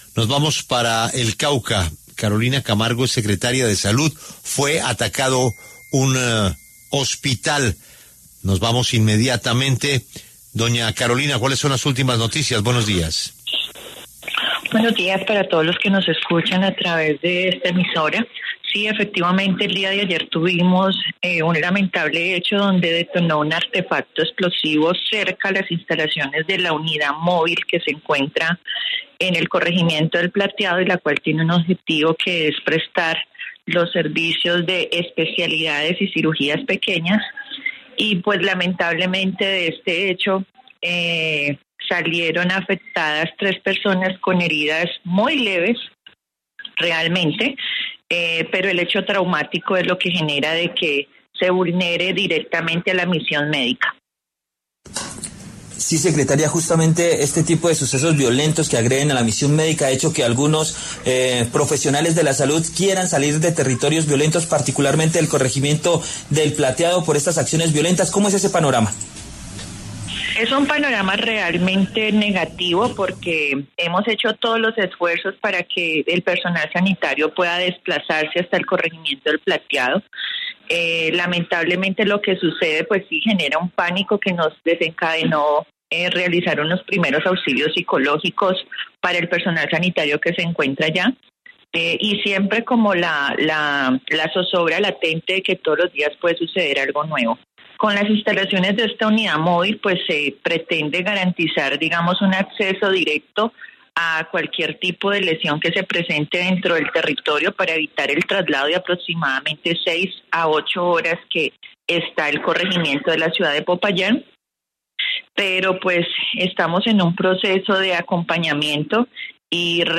En los micrófonos de La W, la secretaria de Salud del Cauca, Carolina Camargo, rechazó el ataque con artefactos explosivos cerca de la unidad móvil de salud en el corregimiento de El Plateado.